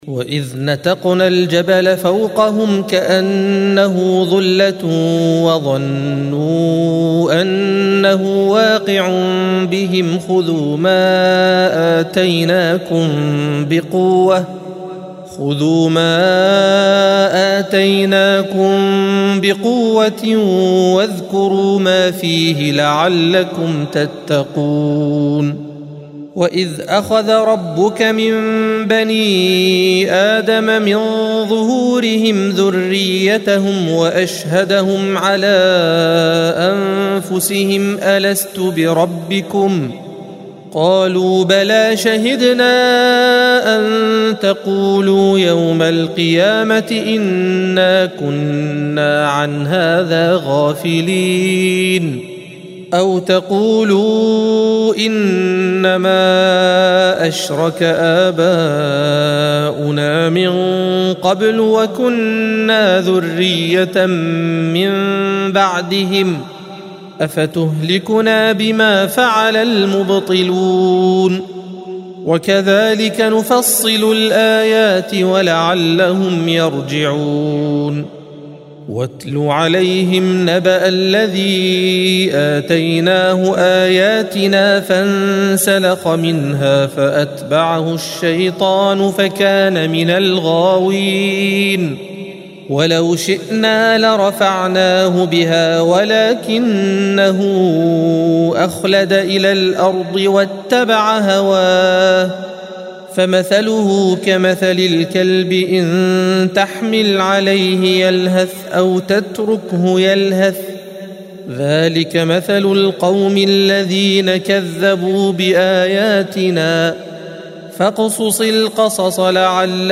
الصفحة 173 - القارئ